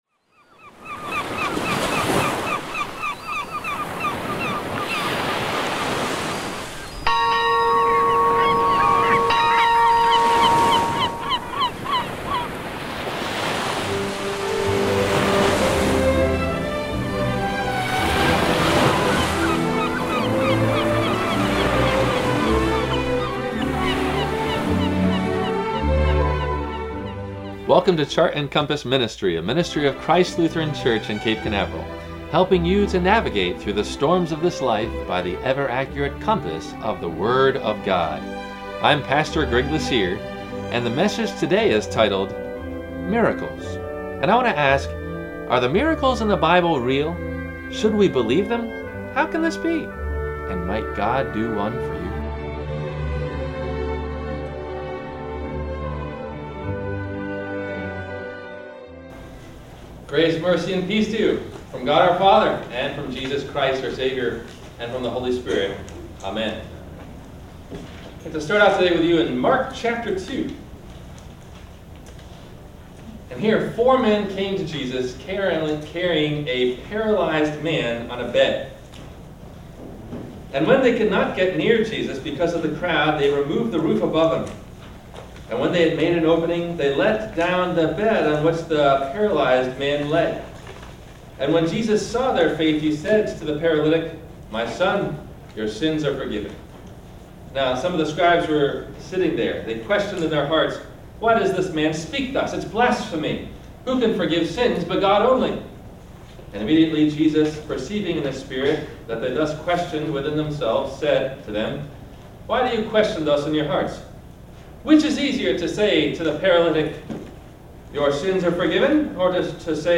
Miracles – WMIE Radio Sermon – June 09 2014